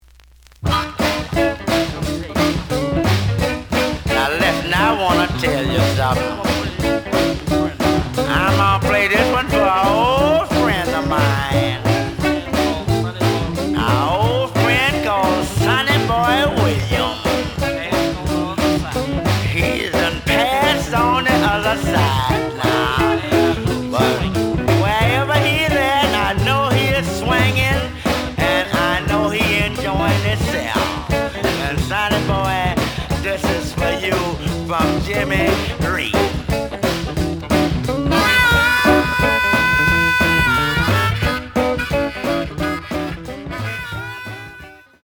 The audio sample is recorded from the actual item.
●Genre: Blues
Slight edge warp.